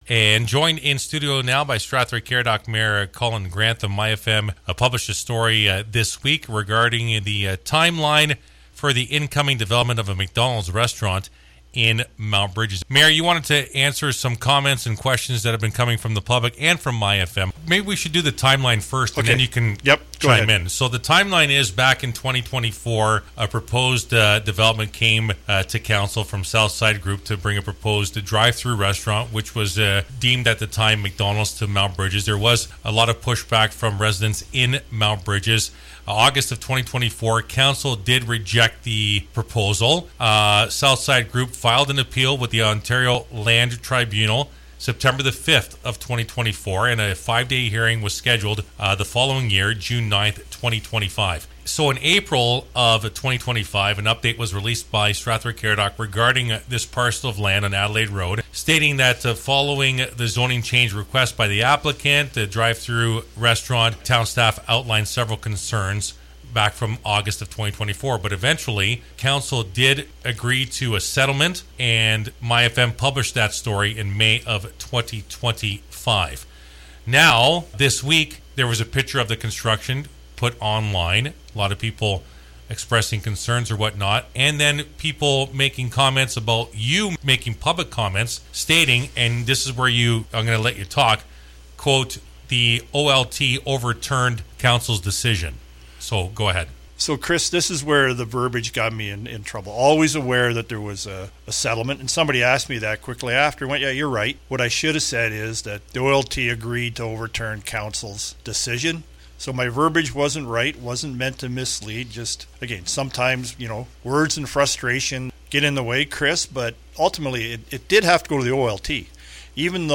The Mayor requested to be heard and the following is the full interview with Mayor Grantham.